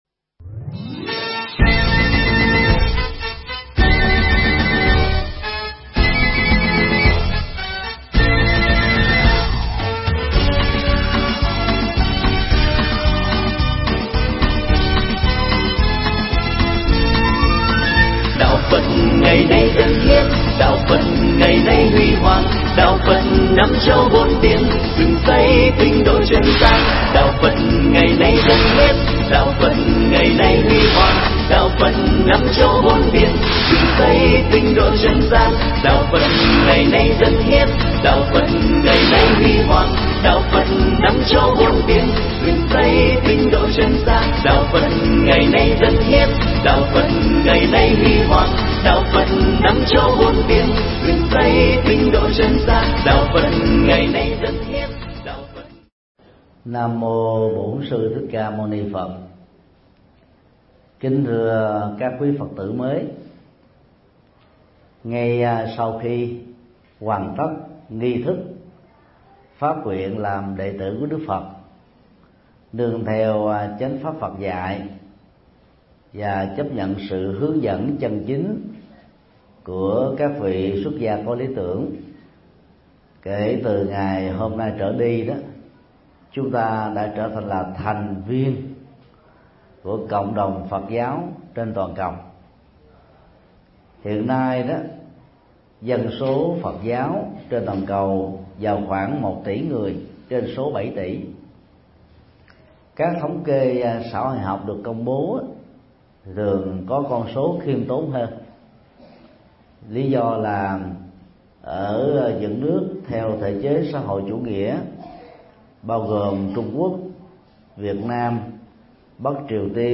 Tải mp3 Pháp thoại Tư cách và sự tu học của Phật tử tại gia do thầy Thích Nhật Từ Giảng tại cơ sở sinh hoạt tạm của chùa Giác Ngộ (139/5 Phan Đăng Lưu, Phường 2, Quận Phú Nhuận), ngày 07 tháng 01 năm 2014